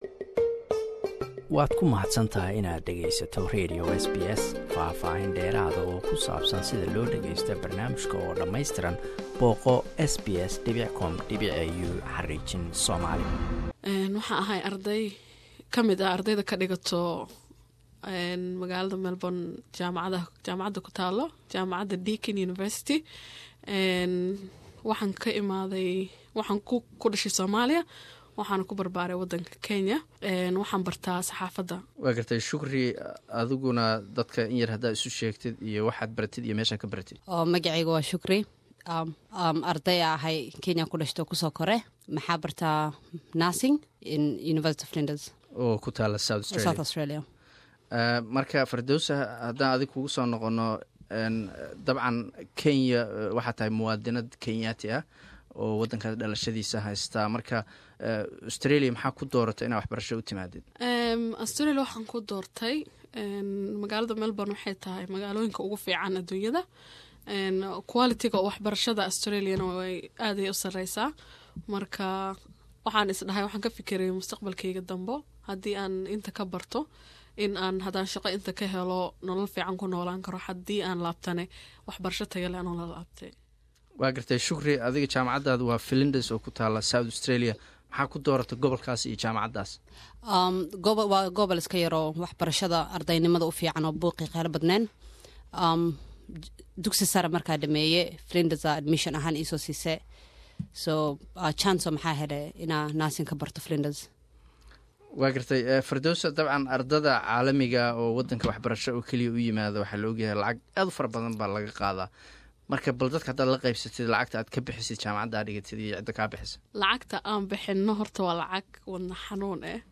Waraysi: Arday Kenyan-Somali ah oo Australia wax ka barta